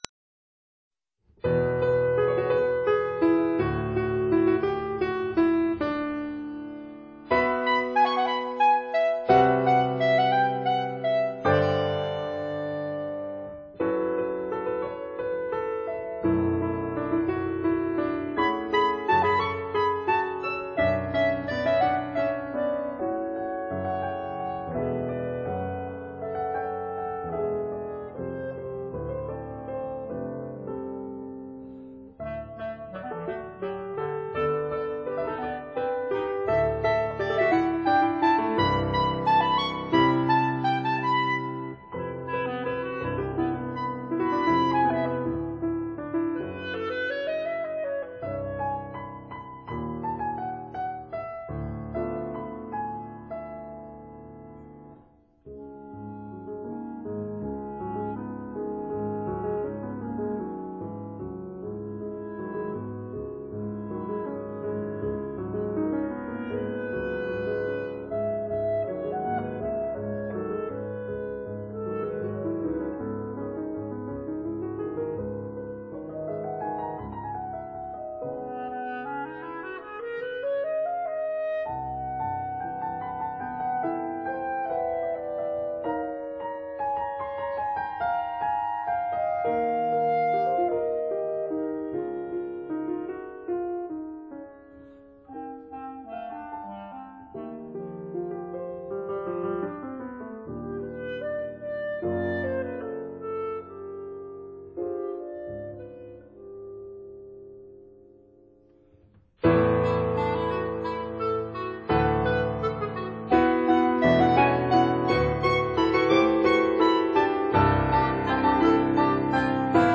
14 pieces for clarinet & piano